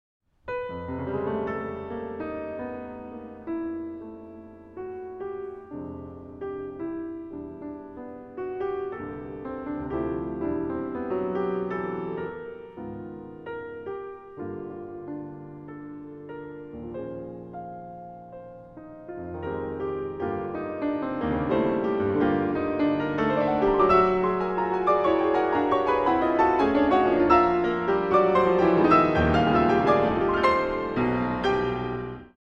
Sonata for Piano